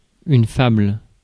Ääntäminen
France, Paris: IPA: [yn fabl]